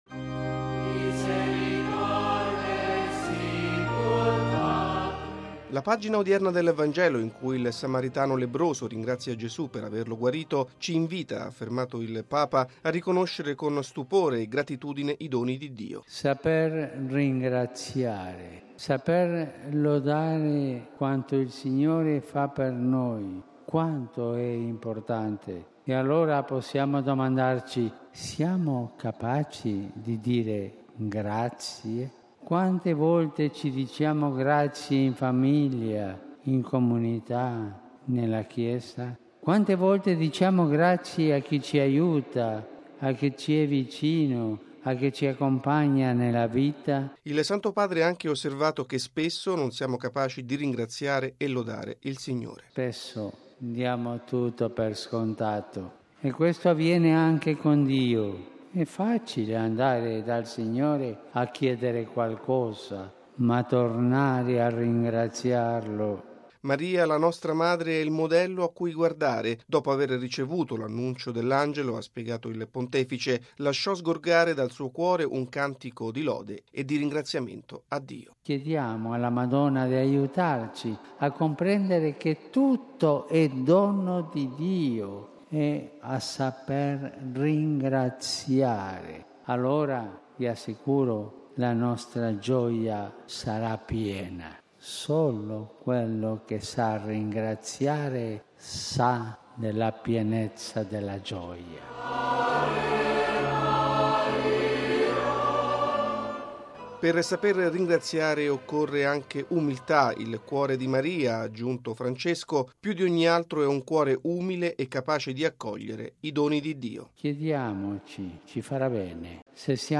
Saper ringraziare significa avere un cuore umile, “capace di accogliere i doni di Dio” e non rinchiudersi nelle proprie sicurezze. E’ quanto ha sottolineato Papa Francesco durante la Santa Messa, in Piazza San Pietro, a conclusione del Giubileo Mariano.